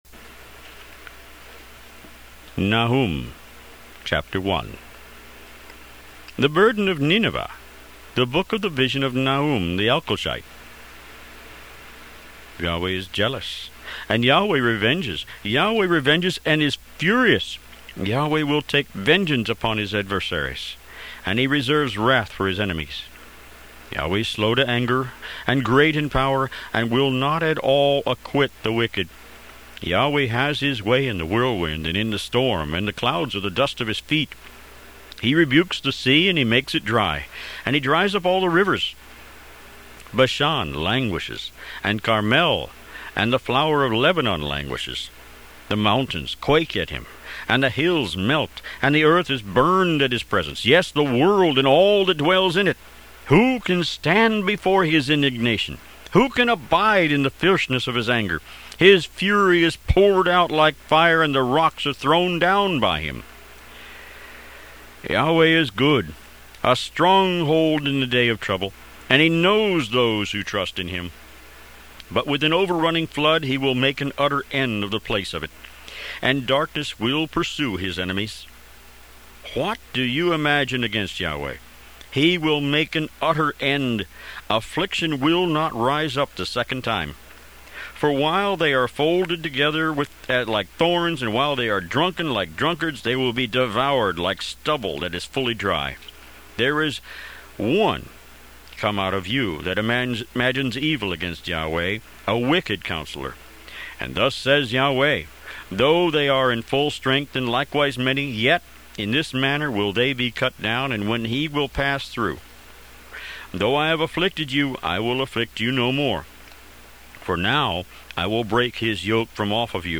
Tanakh - Jewish Bible - Audiobook > 34 Nahum